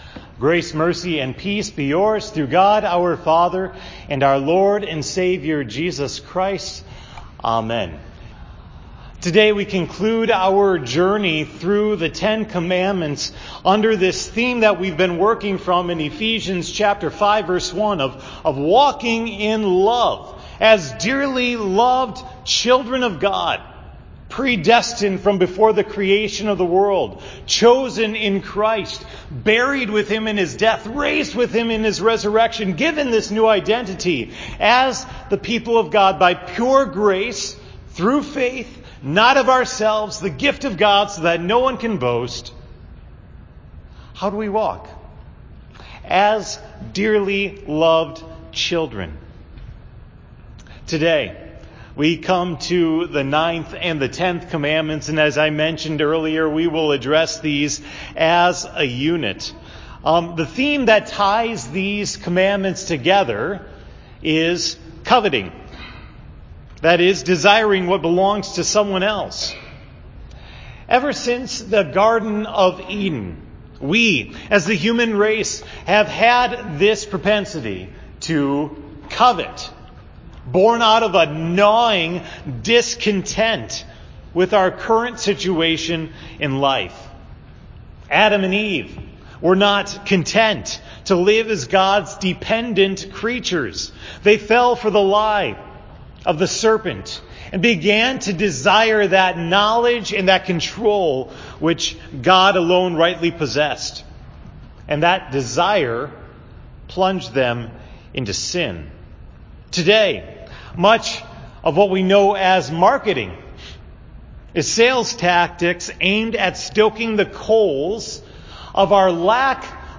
The sermon for August 5, 2018 at Hope Text: Philippians 4:10-13